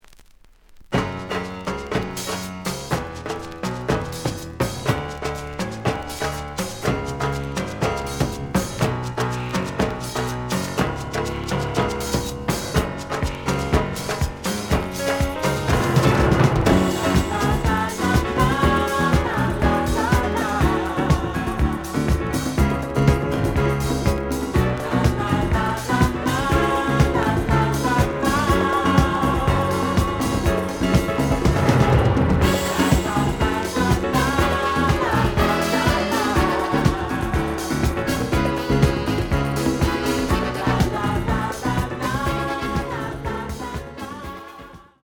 The audio sample is recorded from the actual item.
●Format: 7 inch
●Genre: Soul, 70's Soul
Slight edge warp.